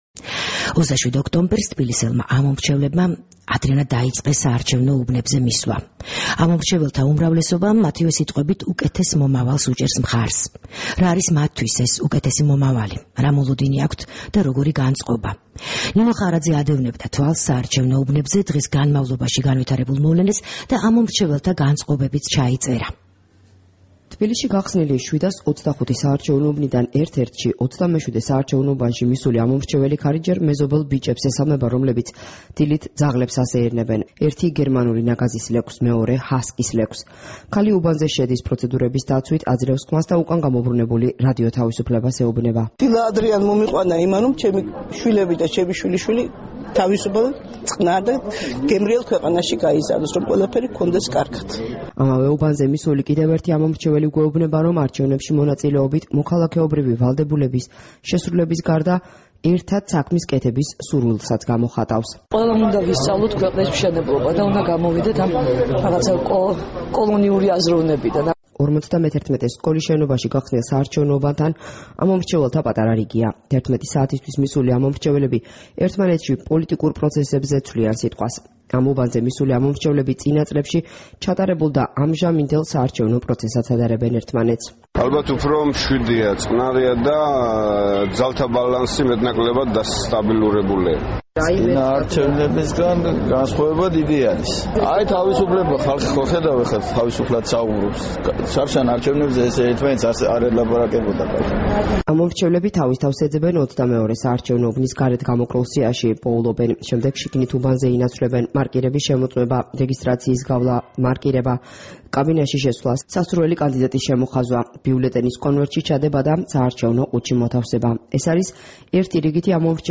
რეპორტაჟი მეექვსე საპრეზიდენტო არჩევნების საარჩევნო უბნებიდან